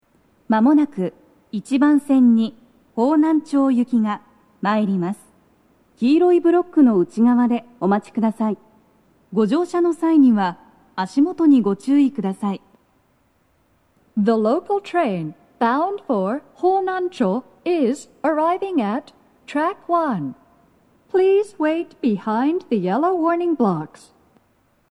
スピーカー種類 BOSE天井型
鳴動は、やや遅めです。
１番線 荻窪・方南町方面 接近放送 【女声